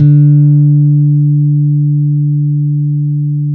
-MM JAZZ D 4.wav